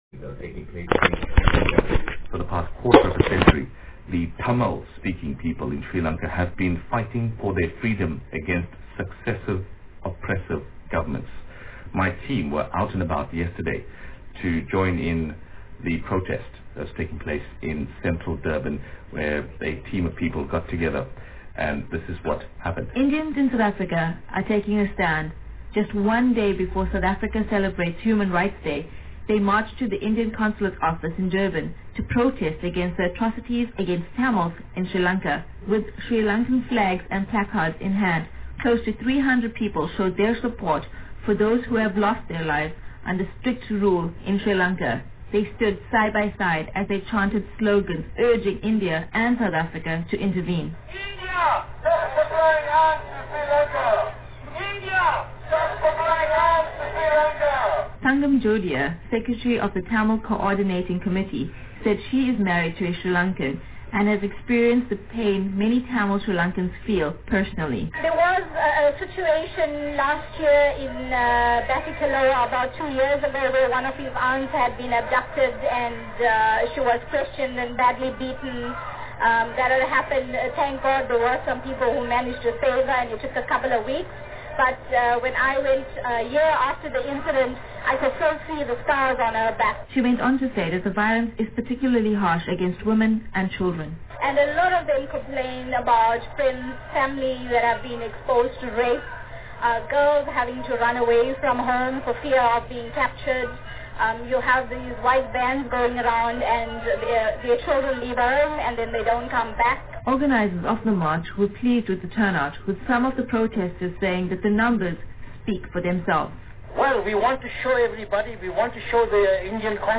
Voice: SABC National Radio coverage